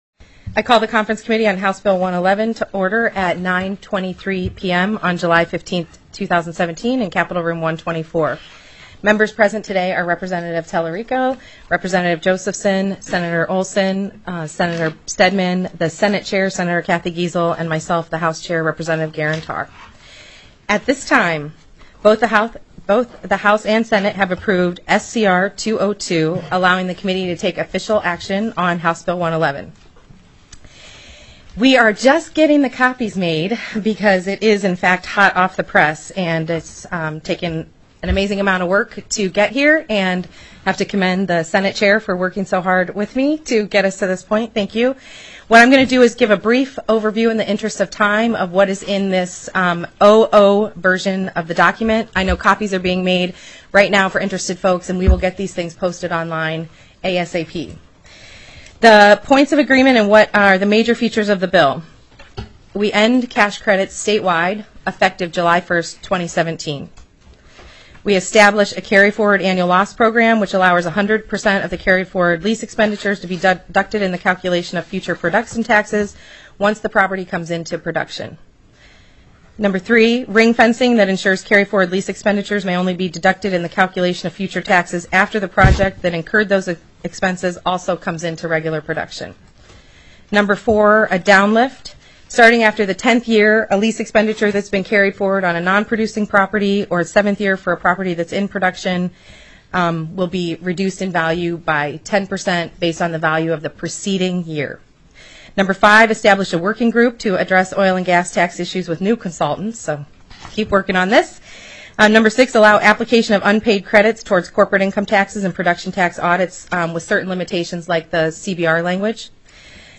07/15/2017 01:00 PM House CONFERENCE COMMITTEE ON HB111
The audio recordings are captured by our records offices as the official record of the meeting and will have more accurate timestamps.
+ teleconferenced